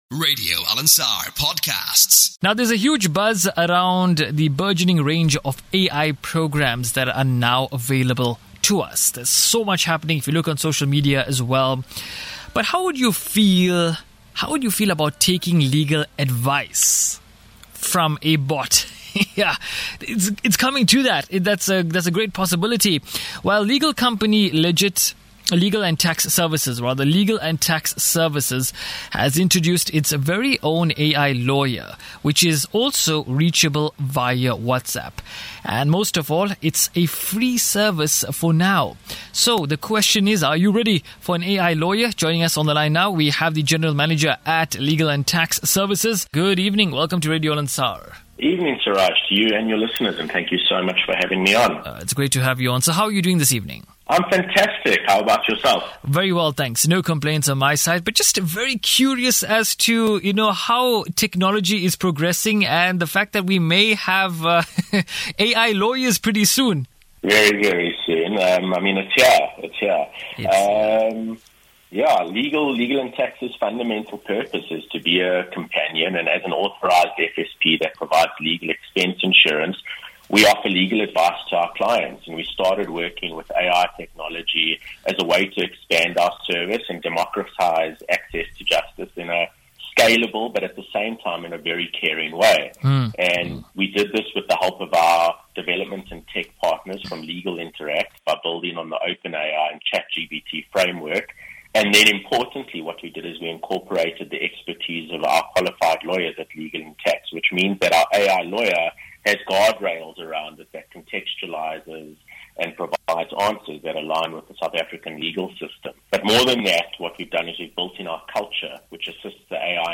We started working with AI technology as a way to expand on our service and democratise access to justice in a scalable but at the same time very caring way. Listen to our recent interview on Radio Alansaar here.